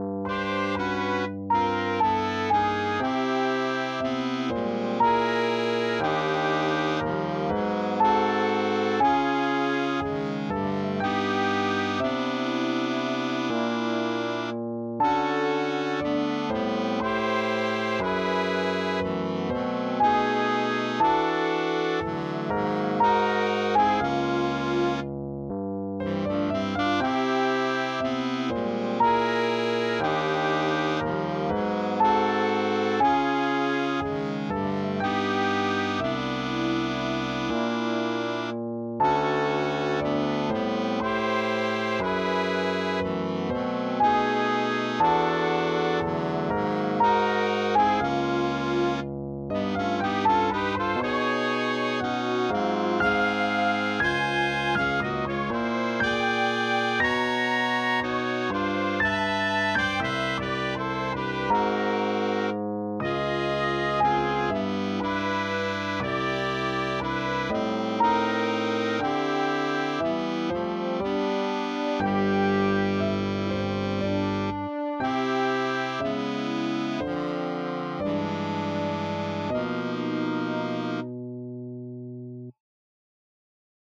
MidiDemo.m4a